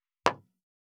194,コップ,工具,小物,雑貨,コトン,トン,ゴト,ポン,ガシャン,ドスン,ストン,カチ,タン,バタン,スッ,サッ,コン,ペタ,パタ,チョン,コス,カラン,ドン,チャリン,効果音,環境音,BGM, – EFFECT-CUE